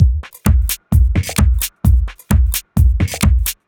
Minimal Funk 02.wav